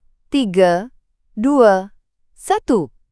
countdown321.wav